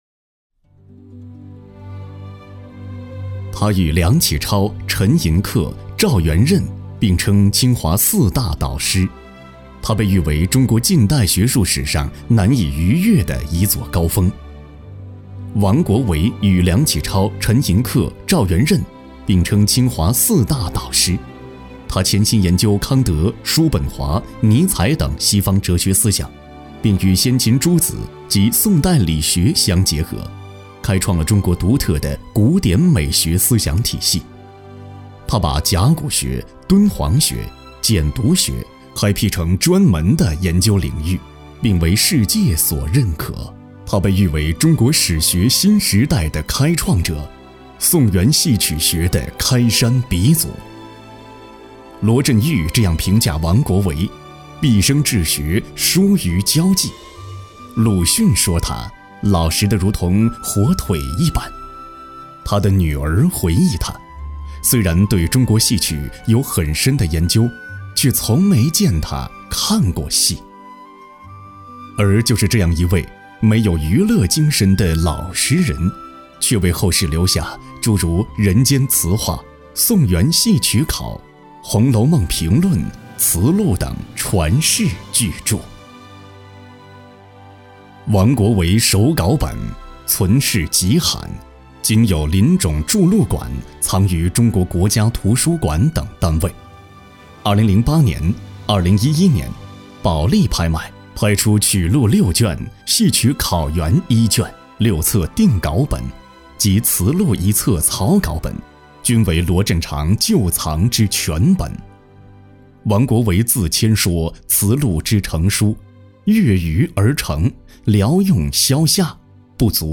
国语青年沉稳 、积极向上 、男专题片 、宣传片 、60元/分钟男9 国语 男声 宣传片 大气时尚--云雨科技 沉稳|积极向上